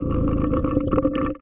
Sfx_creature_spikeytrap_idle_os_04.ogg